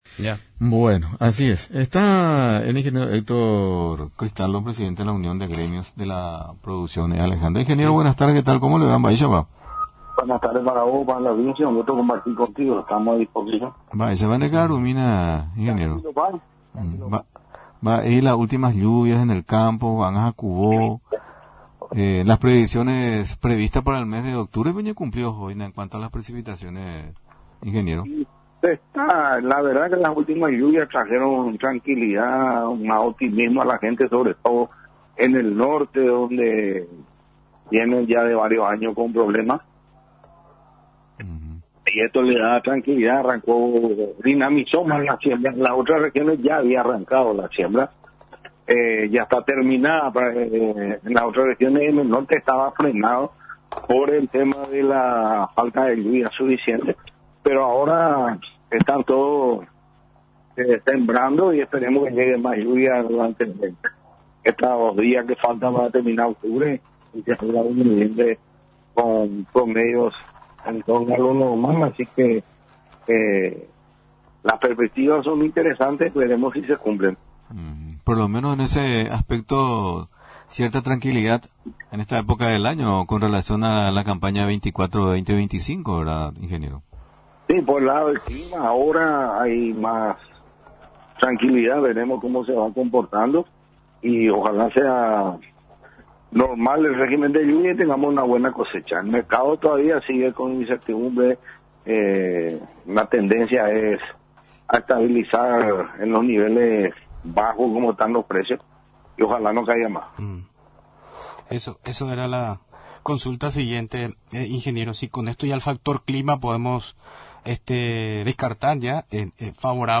Aseguró, en entrevista en el programa Actualidad en Debate, que el clima para arrancar la siembra está bien, pero para la cosecha conocer el comportamiento del clima.